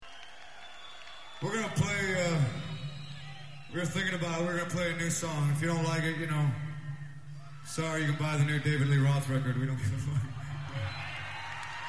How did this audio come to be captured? Right before they play a new song(3/29/94).